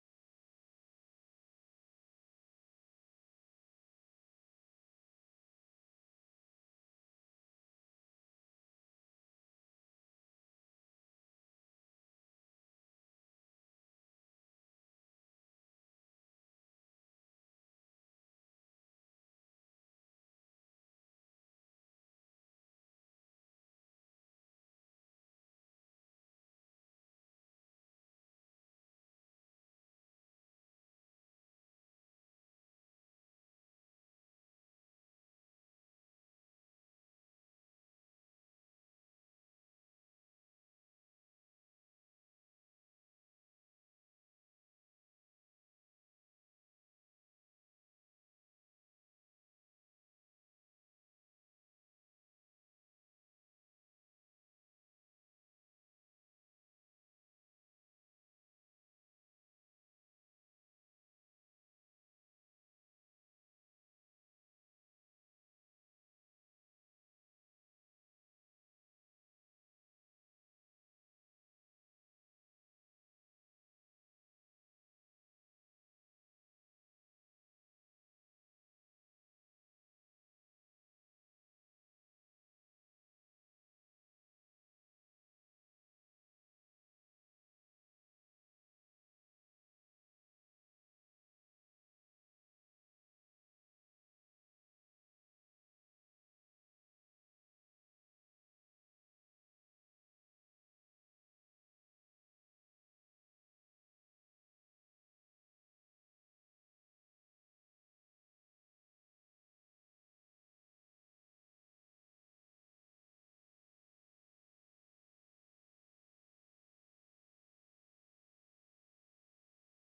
全然献上 Preacher
使徒行传 6:10-15，7:51-60 Service Type: 主日崇拜 欢迎大家加入我们的敬拜。